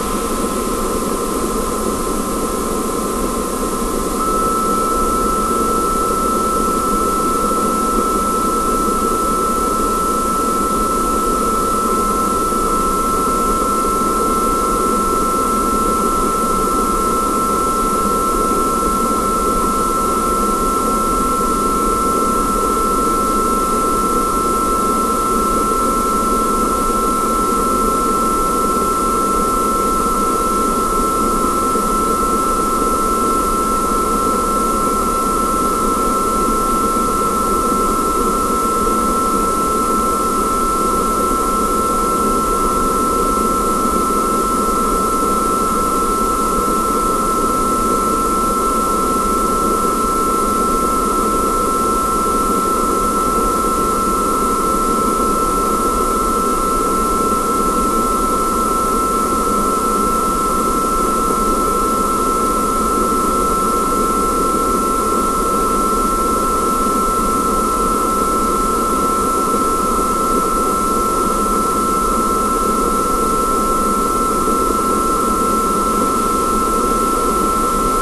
I recorded Mac Studio whistling at a sampling rate of 96000 Hz. I edited the sound, limiting the frequencies outside of 2000 Hz, about 2048 Hz and 2700 Hz I highlighted.
Despite all this, it's impossible to tell if it's the coils or the mechanical sound.